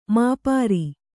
♪ māpāri